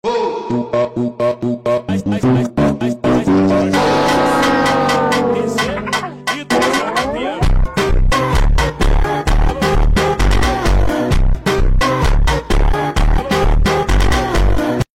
HIGH BASS PHONK 🔥 HIGH ENERGY PHONK
FUNK SONG BRAZILIAN PHONKS